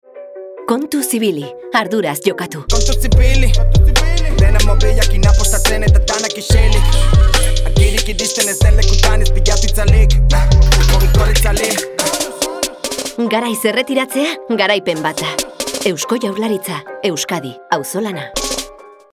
LAS ACCIONES SE COMPLETAN CON ANUNCIOS EN EUSKERA Y CASTELLANO, CUÑAS RADIOFÓNICAS Y PUBLICIDAD EN MEDIOS.
CUÑA: